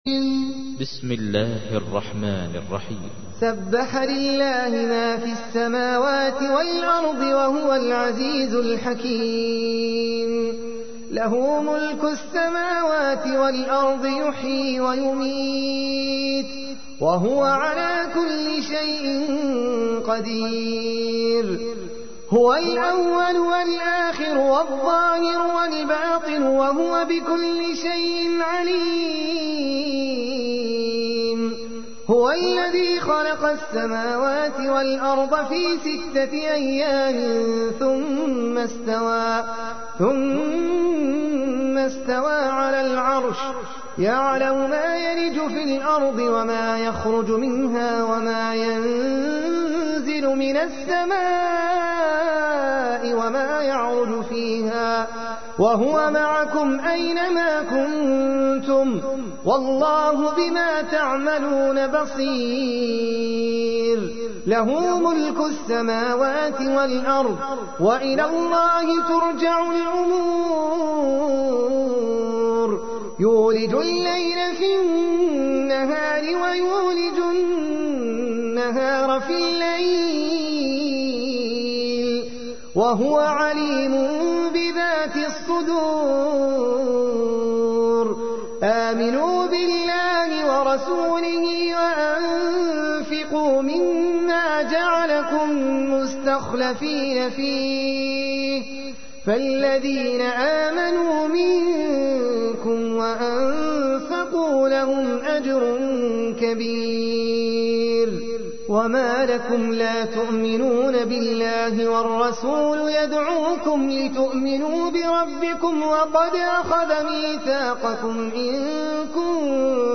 تحميل : 57. سورة الحديد / القارئ احمد العجمي / القرآن الكريم / موقع يا حسين